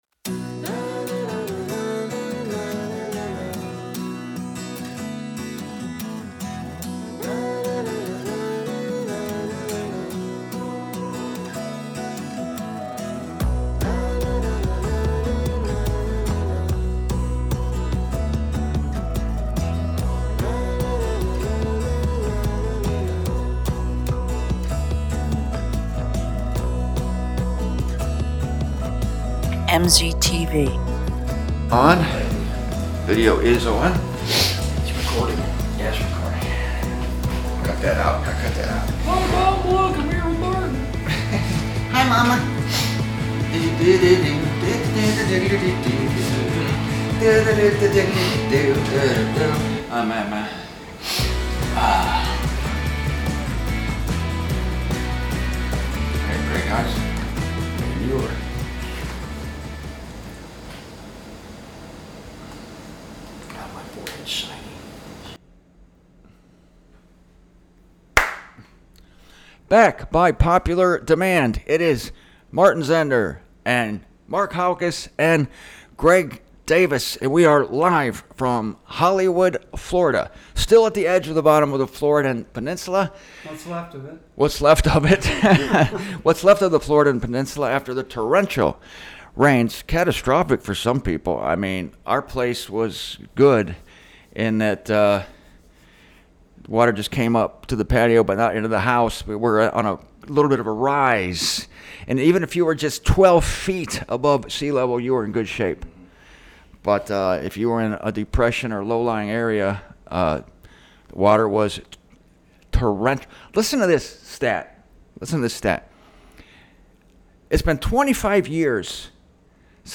The conversation continues, on location in Hollywood, Florida.